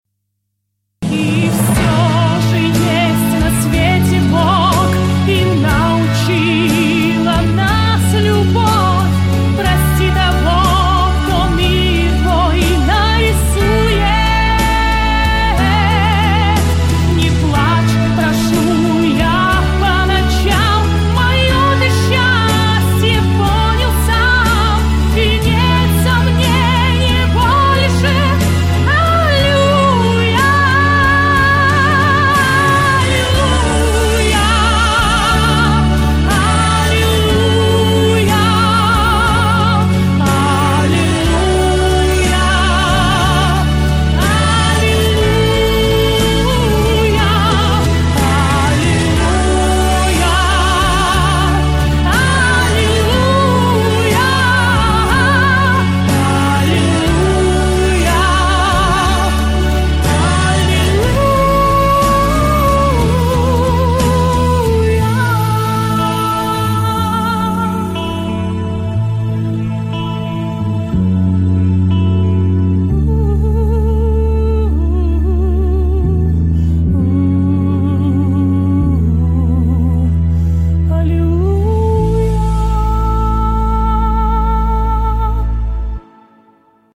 Я контртенор по тембру. пою как в эстрадном так и в оперном жанрах.